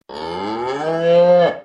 Мычание коровы
Отличного качества, без посторонних шумов.
126_korova.mp3